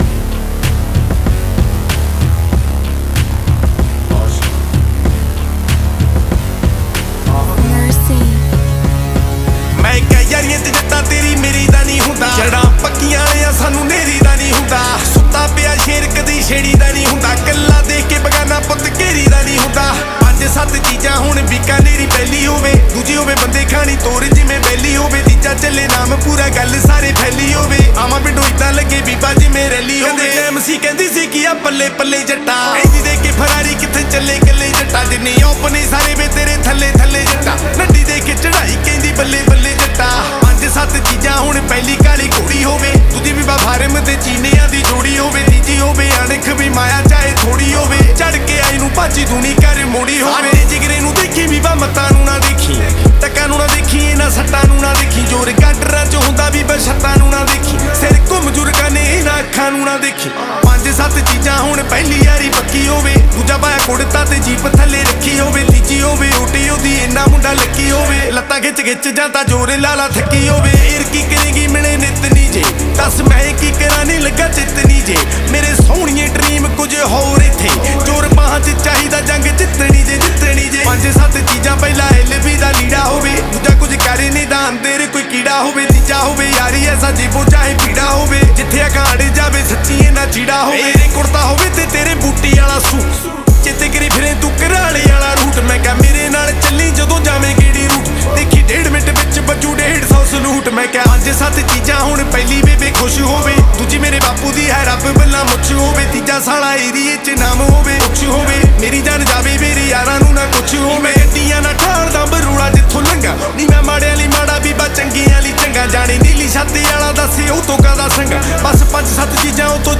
Releted Files Of Latest Punjabi Song